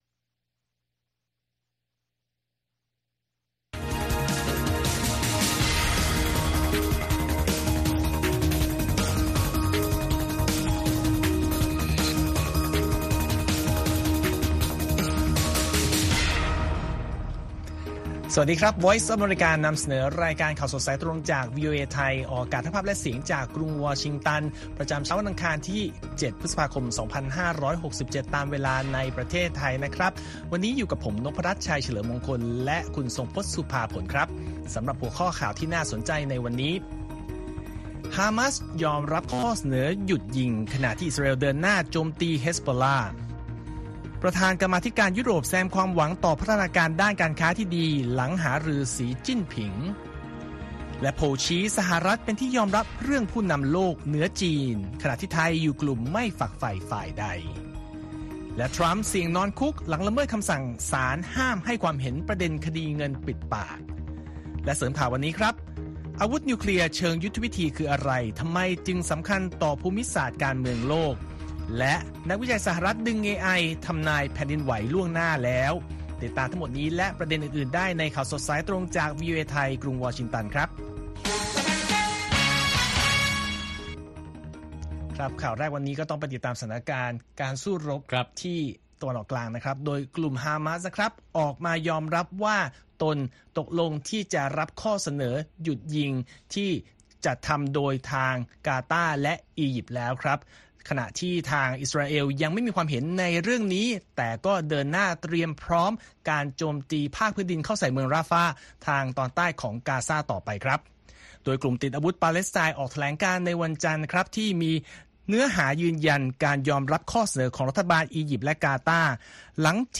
ข่าวสดสายตรงจากวีโอเอไทย 8:30–9:00 น. วันอังคารที่ 7 พฤษภาคม 2567